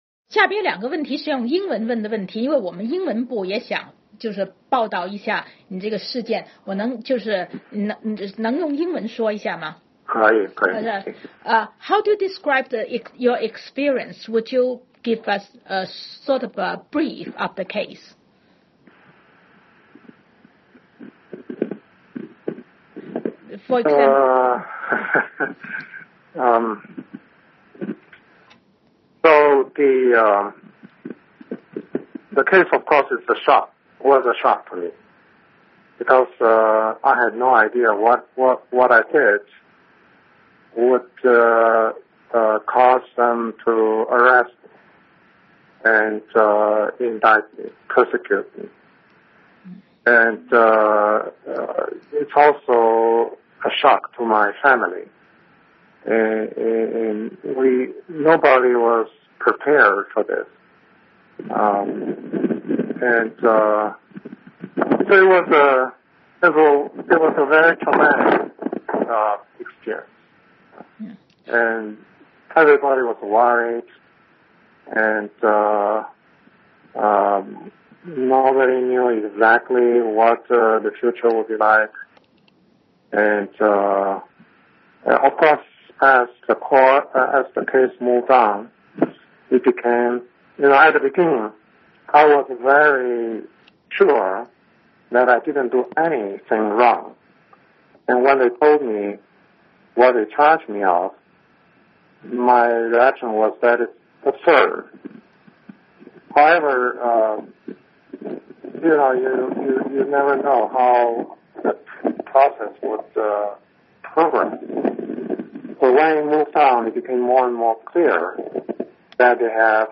In an interview with VOA's Mandarin service